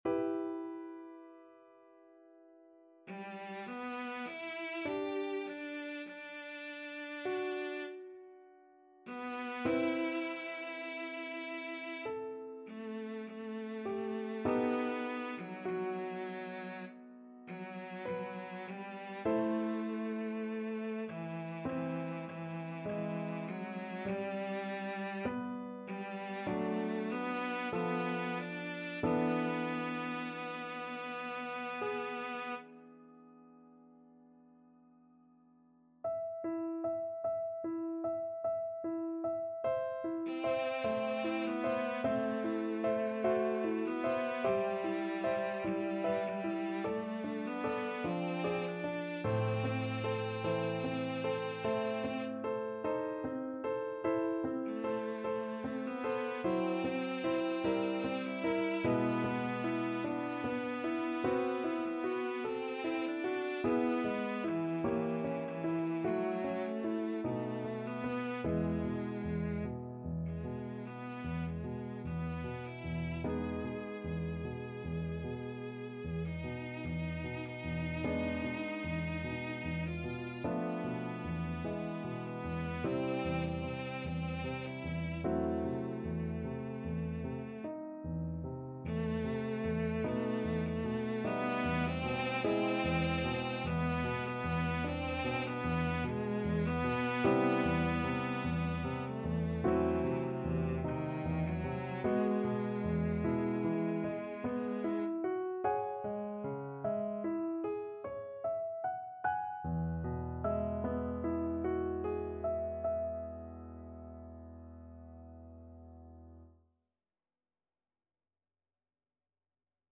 Classical Rachmaninoff, Sergei 12 Romances Op 21, No. 3 Twilight Cello version
Cello
G major (Sounding Pitch) (View more G major Music for Cello )
4/4 (View more 4/4 Music)
~ = 100 Lento =50
Classical (View more Classical Cello Music)